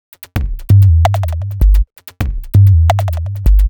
Mice3 130bpm.wav